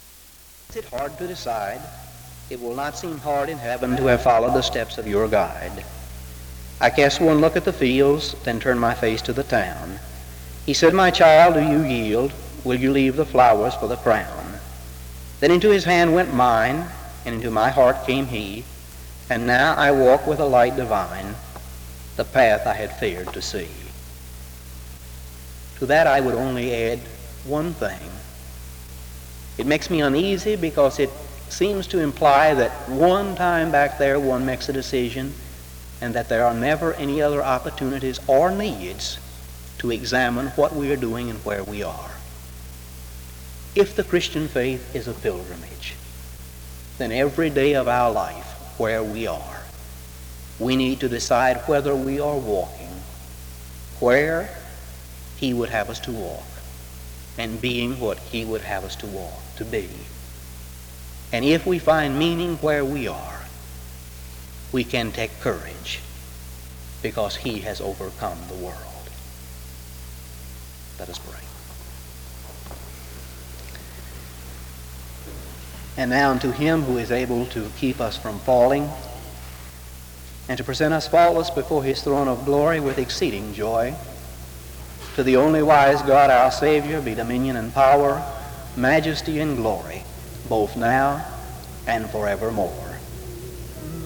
Location Wake Forest (N.C.)
SEBTS Chapel and Special Event Recordings